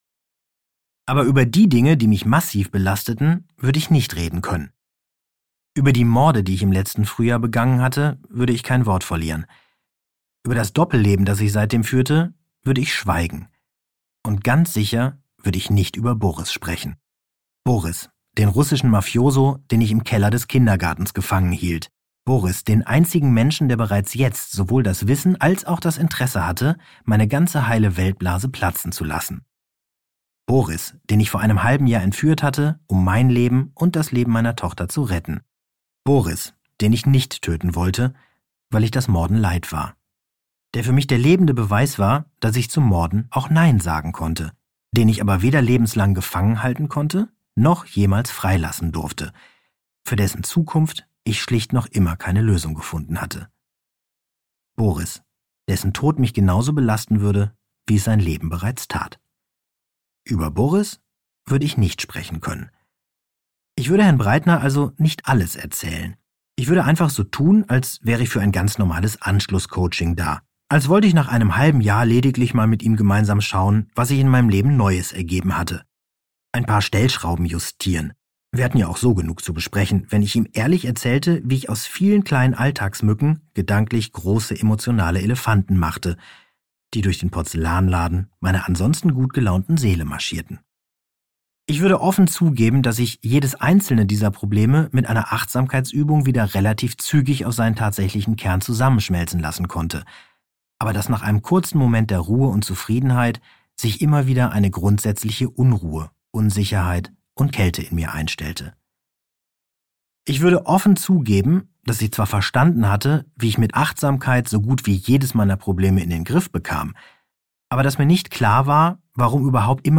Audio knihaDas Kind in mir will achtsam morden (DE)
Ukázka z knihy
• InterpretKarsten Dusse